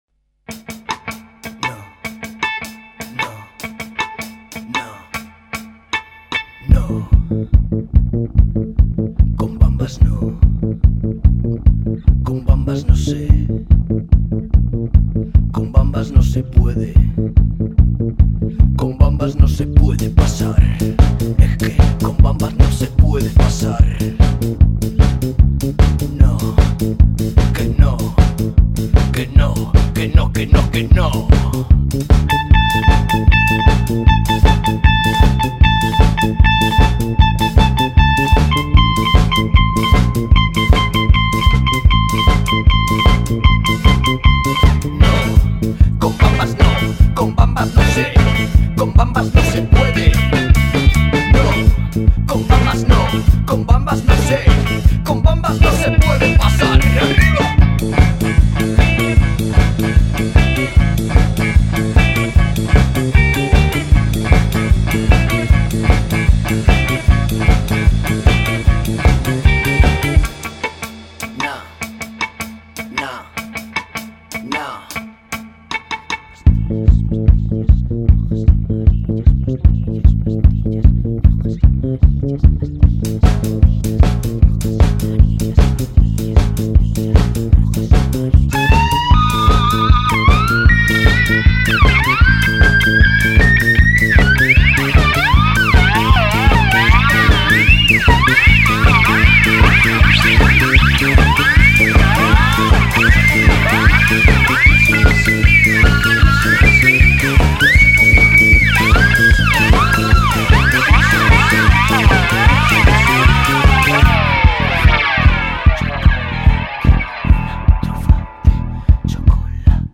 cuarteto